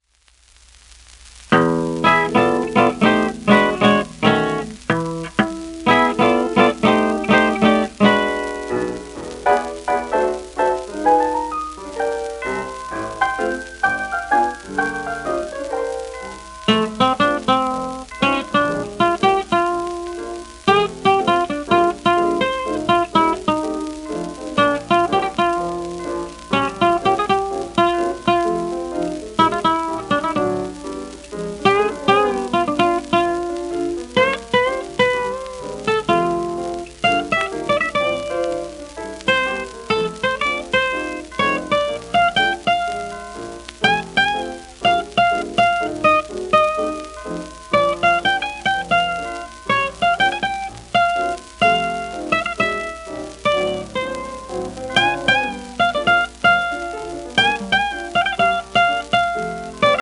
1928年録音
ジャズギターの父と称される伝説的ギタリストの貴重なソロ録音